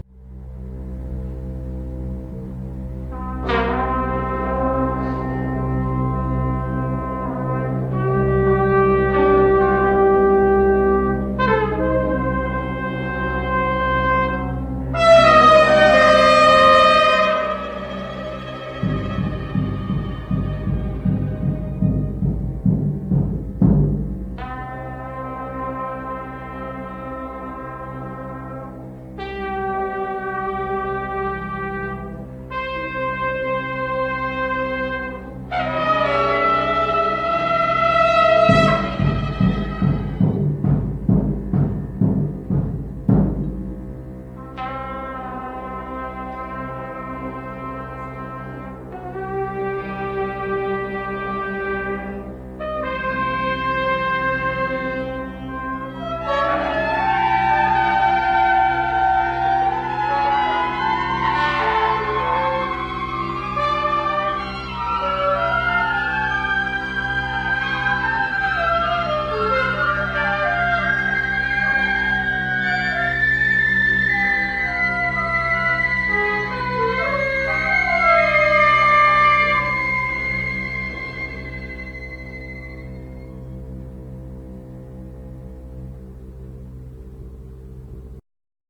There's this group called the Portsmouth Symphonia. I think they swap instruments or something. This rendidion of Zarathustra is pretty brutal.